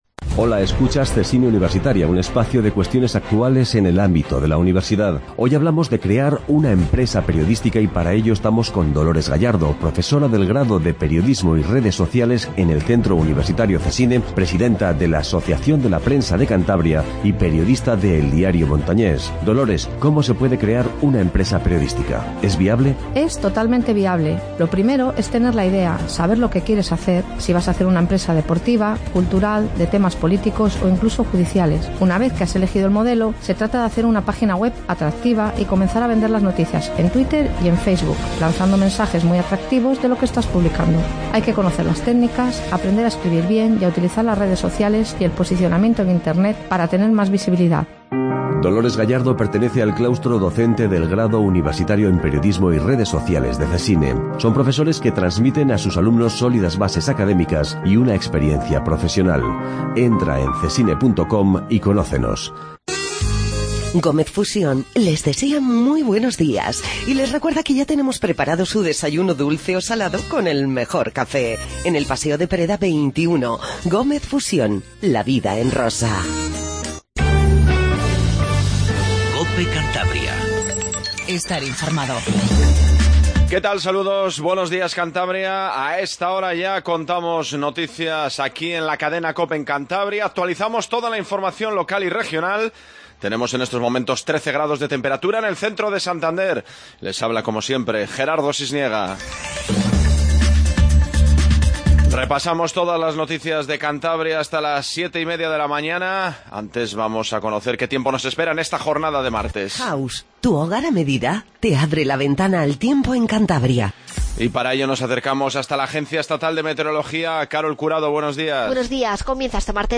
INFORMATIVO MATINAL 07:20